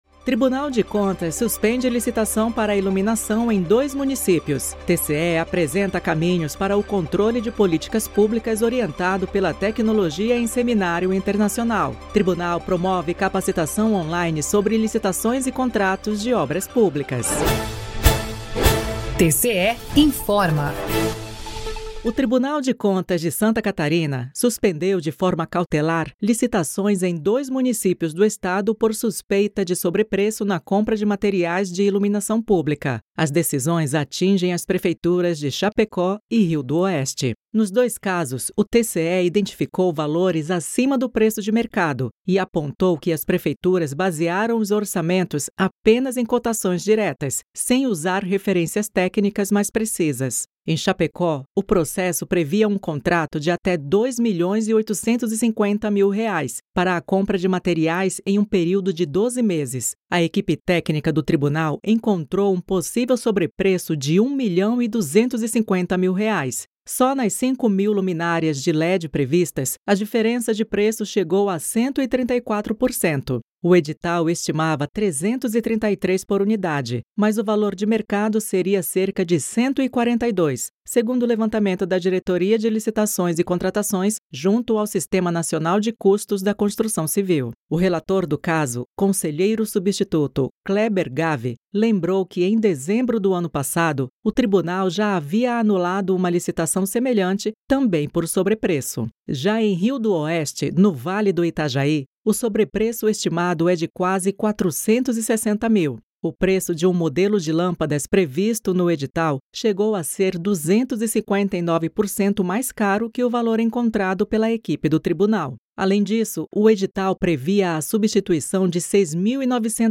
VINHETA TCE INFORMOU